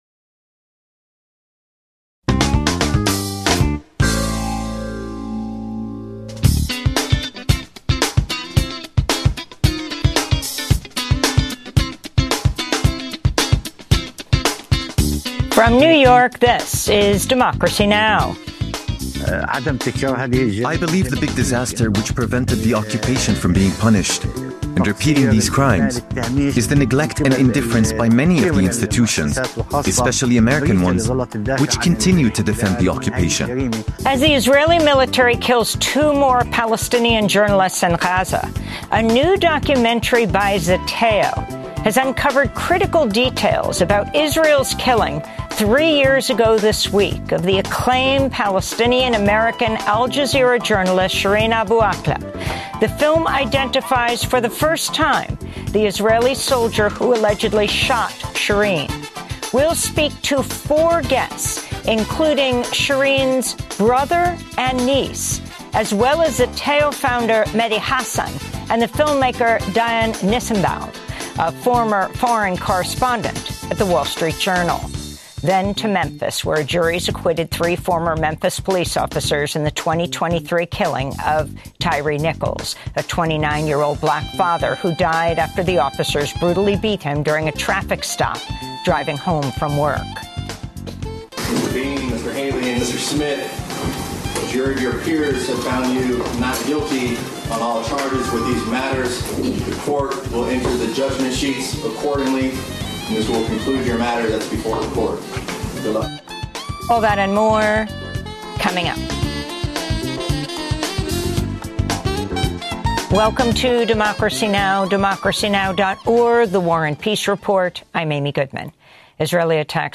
daily, global, independent news hour